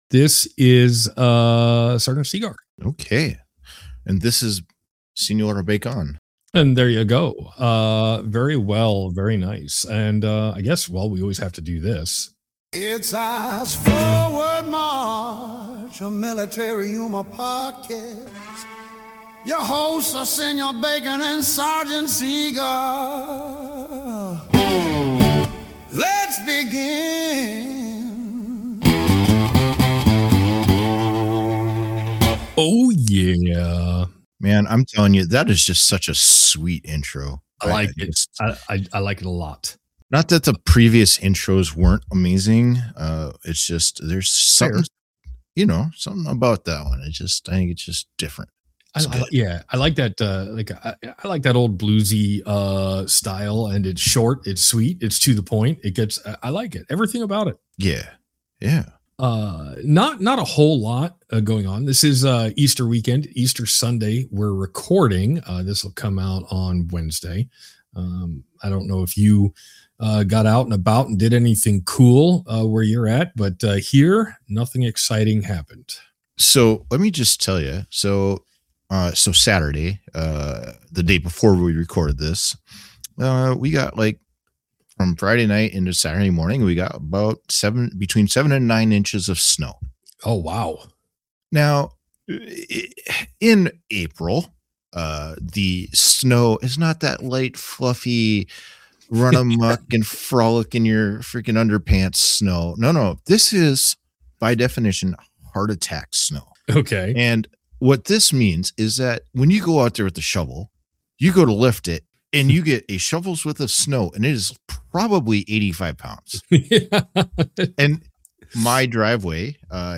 Follow the conversations and stories of a few Non-Commissioned Officers (NCOs) from all over the country who met while attending a leadership course.
Be a guest on this podcast Language: en Genres: Comedy Contact email: Get it Feed URL: Get it iTunes ID: Get it Get all podcast data Listen Now...